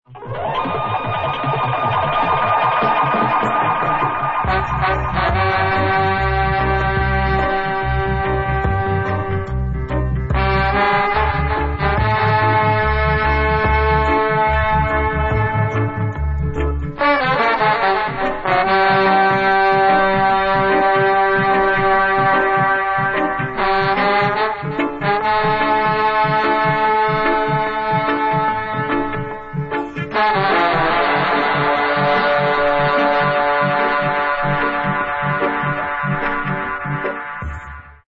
DUB